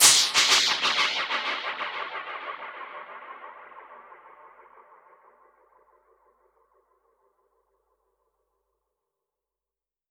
Index of /musicradar/dub-percussion-samples/95bpm
DPFX_PercHit_B_95-02.wav